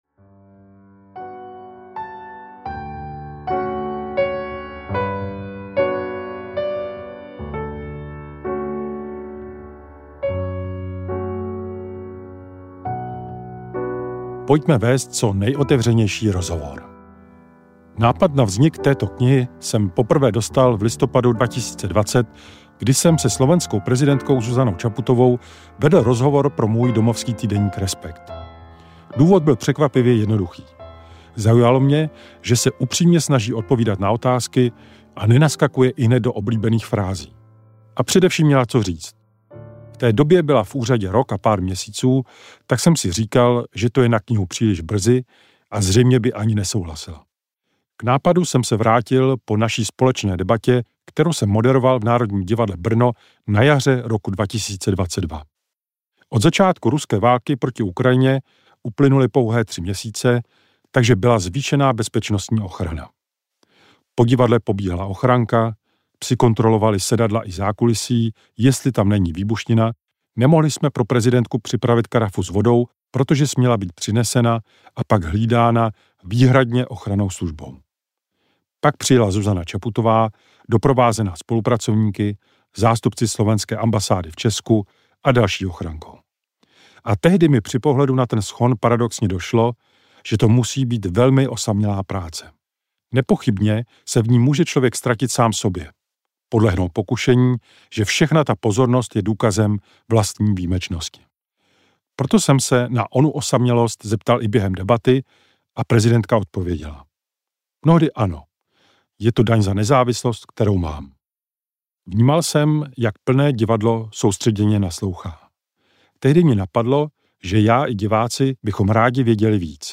Ukázka z knihy
• InterpretErik Tabery, Zuzana Čaputová